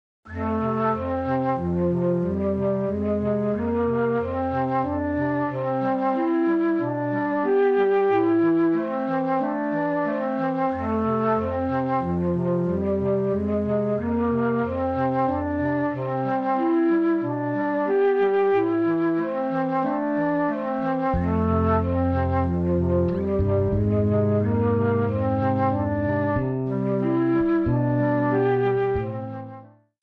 Am
Backing track Karaoke
Pop, 2000s